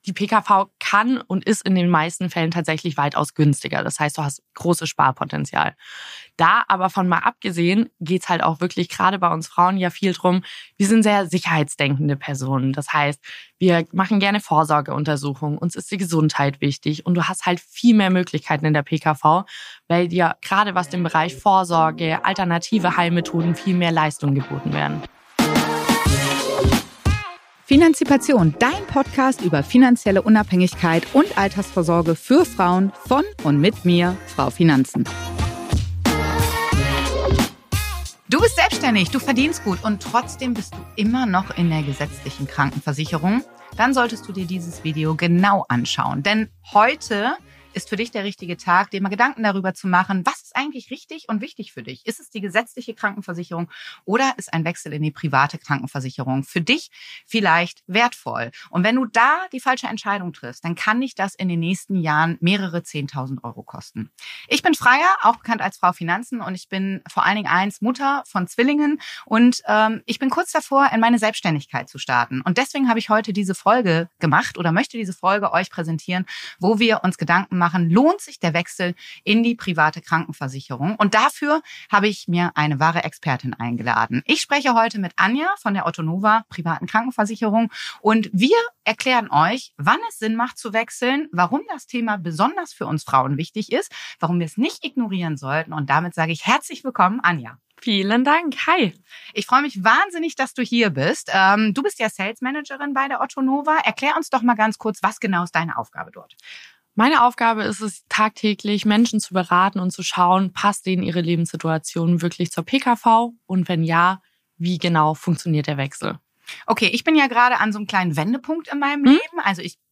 #6: Lohnt sich der Wechsel zur privaten Krankenversicherung? Ein Gespräch